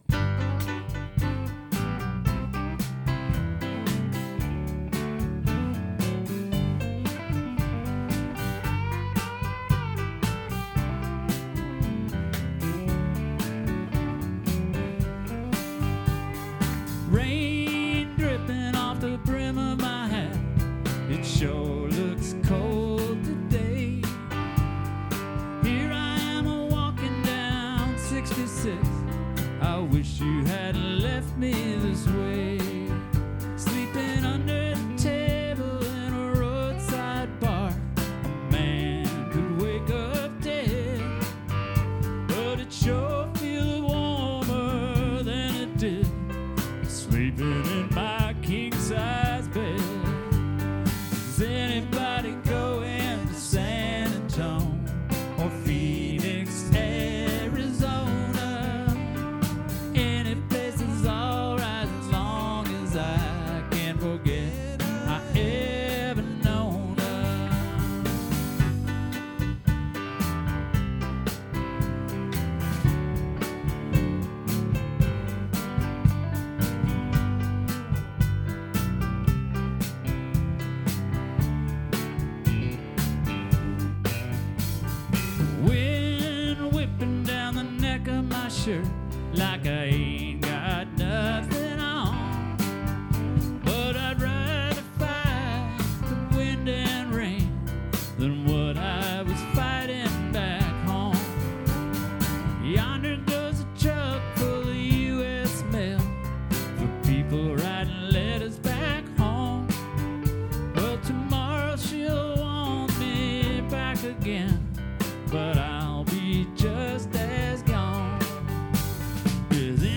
guitarist
bassist
drummer
Is Anybody Goin to San Antone Garrison Hughes Rehearsal, 4/23/2025